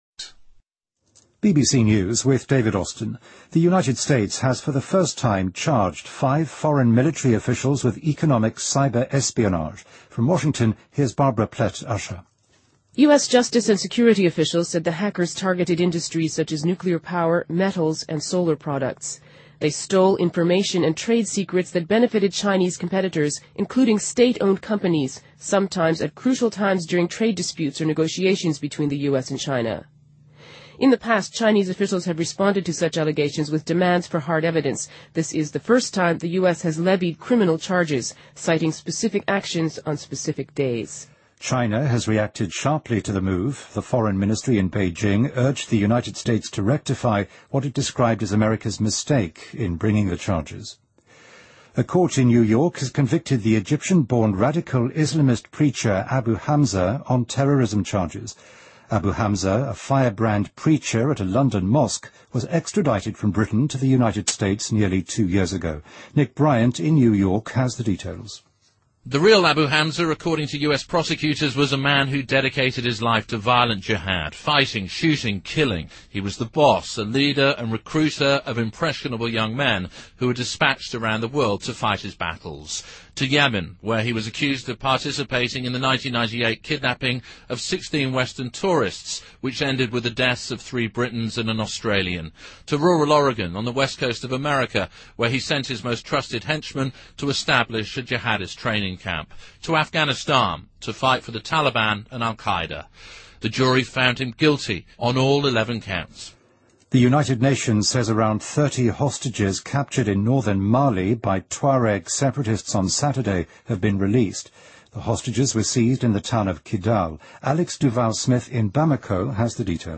BBC news,纽约法院判定埃及出生的极端伊斯兰牧师哈姆扎犯下恐怖罪